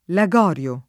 [ la g0 r L o ]